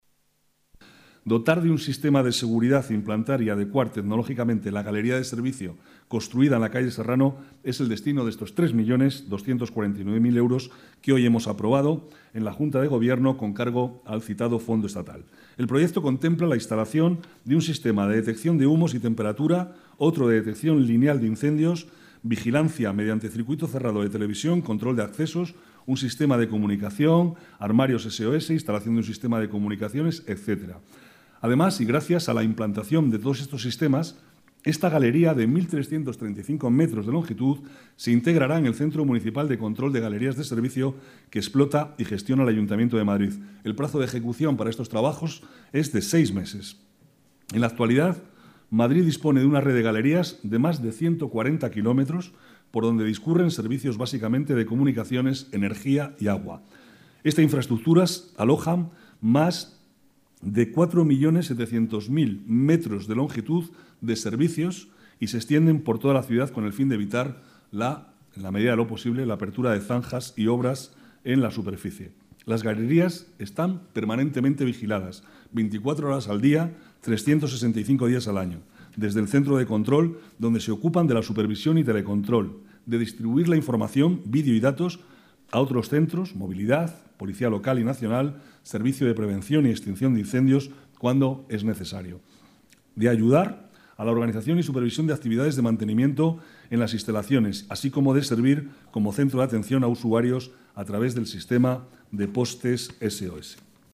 Nueva ventana:Declaraciones vicealcalde, Manuel Cobo: seguridad galería servicios Serrano